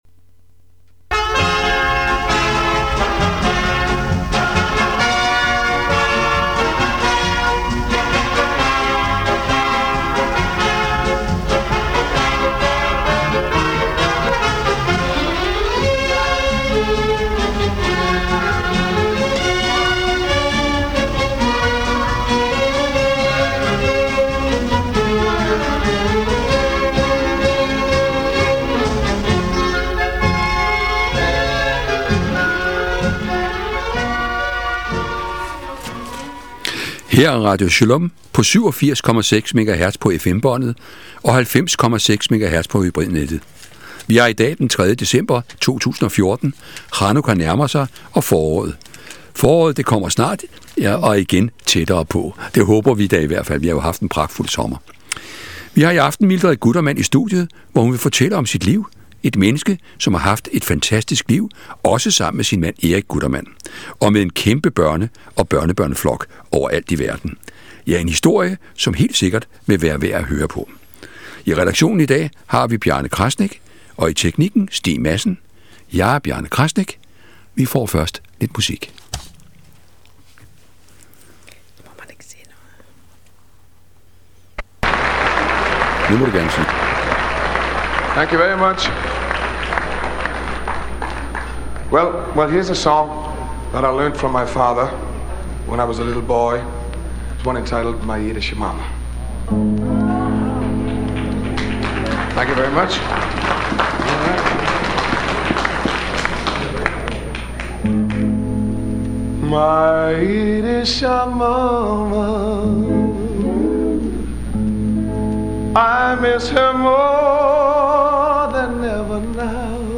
Beskrivelse:Interview